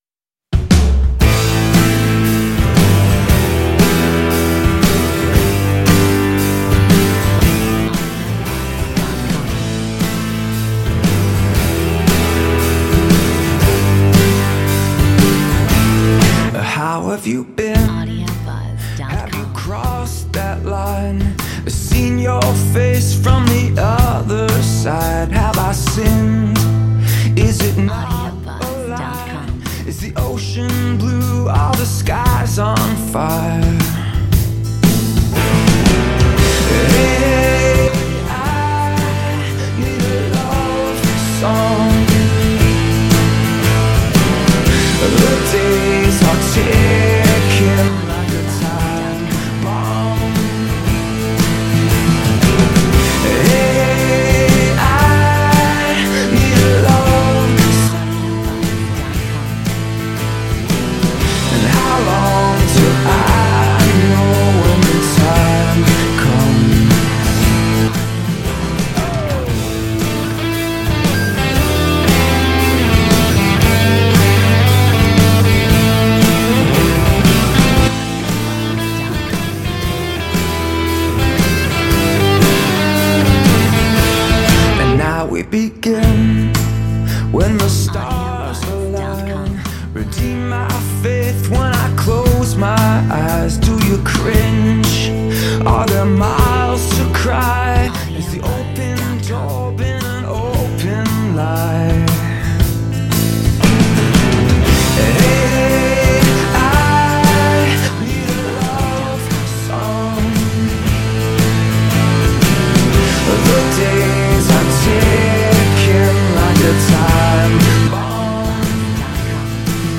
Metronome 117